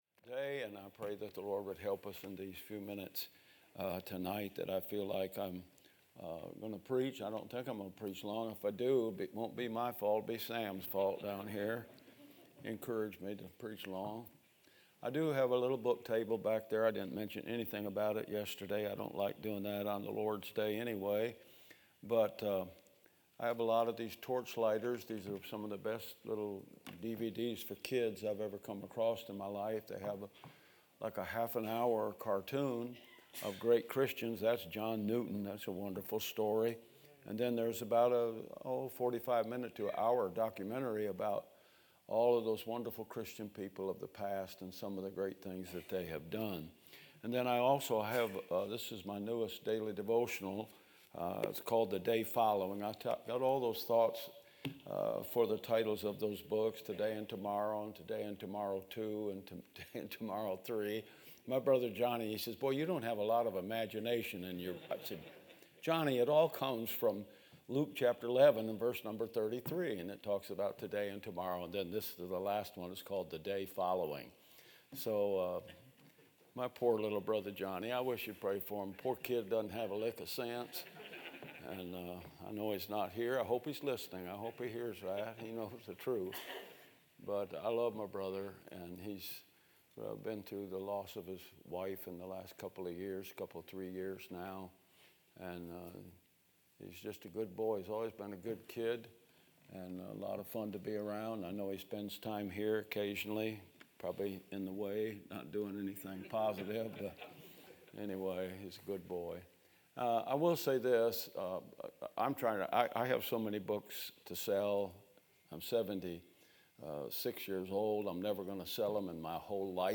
Sermons - Bible Baptist Church
From Series: "2025 Spring Revival"